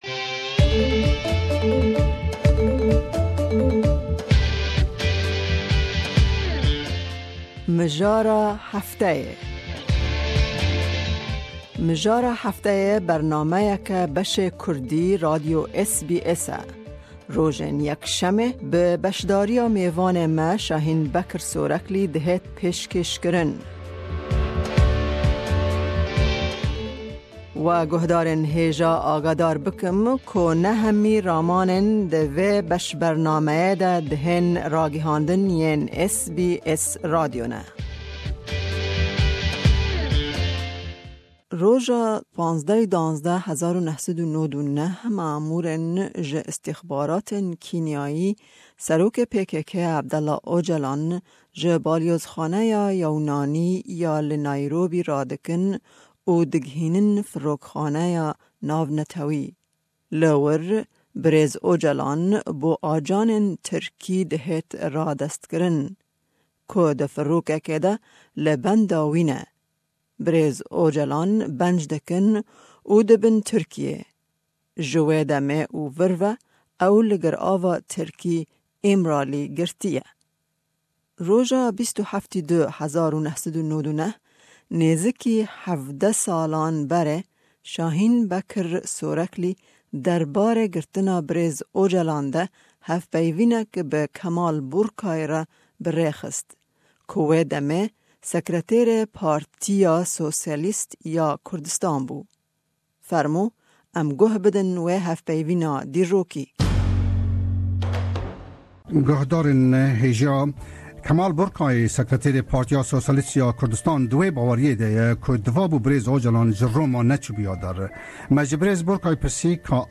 Hevpeyvîneke dîrokî derbarê girtina serokê PKK Abdullah Ocalan de pêsh 17 salan